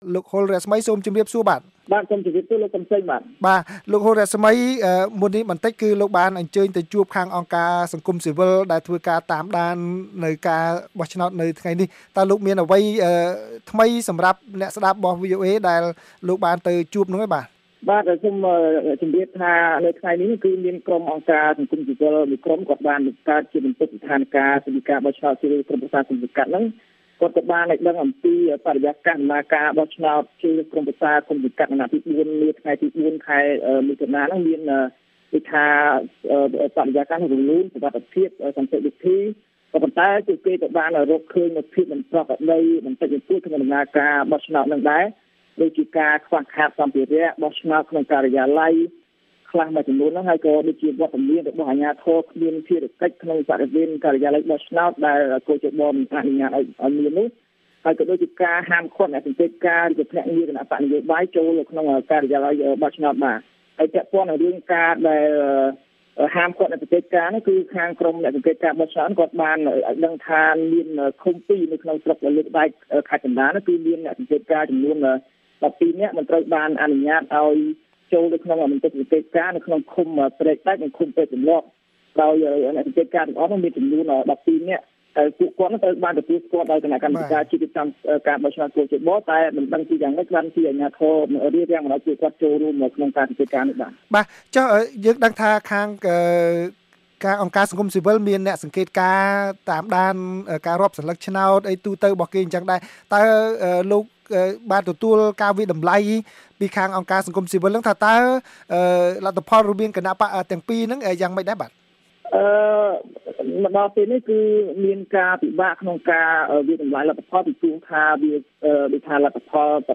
បទសម្ភាសន៍ VOA៖ អង្គការសង្គមស៊ីវិលបង្ហាញការអង្កេតលើដំណើរការបោះឆ្នោតឃុំសង្កាត់អាណត្តិទី៤